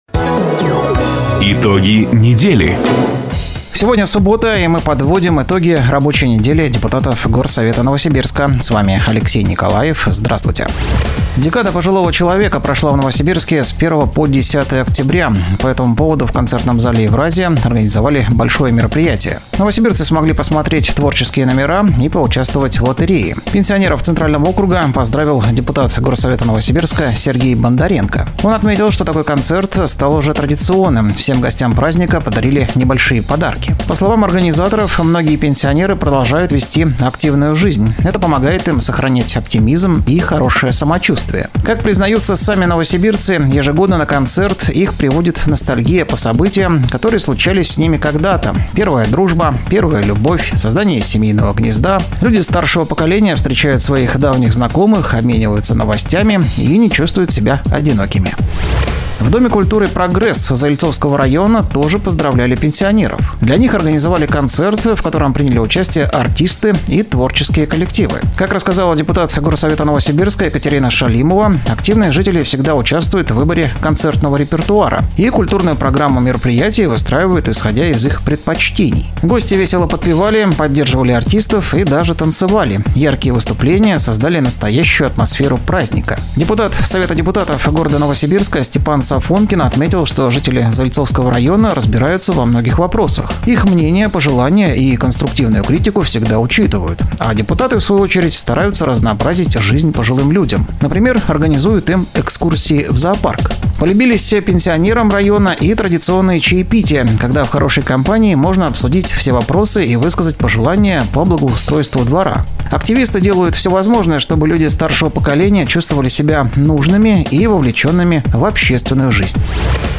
Запись программы "Итоги недели", транслированной радио "Дача" 12 октября 2024 года.